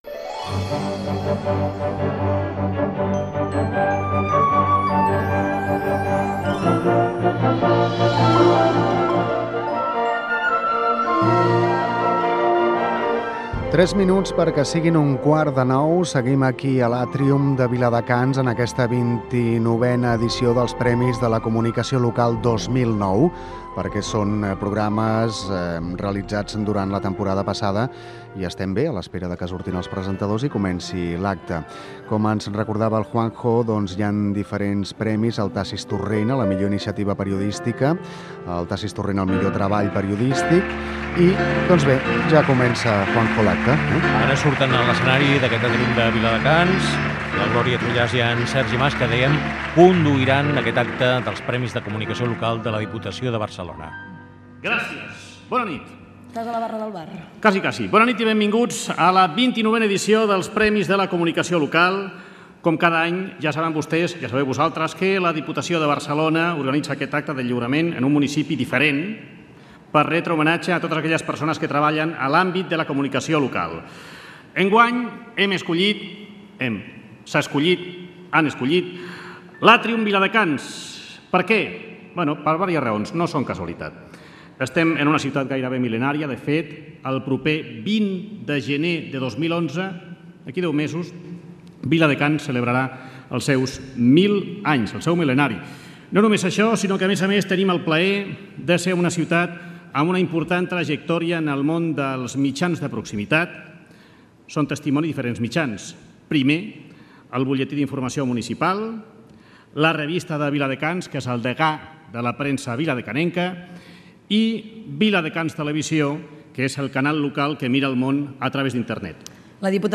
Des de l'Atrium de Viladecans, fragment de la transmissió dels Premis de Comunicació Local